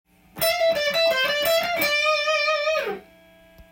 エレキギターのハイポジションで使える
ハイポジフレーズ集
全てCメジャースケール上で使えるようにkeyを変換してあります。
１６分音符と６連符が入り混じった
３連符と４分音符のコンビネーションになります。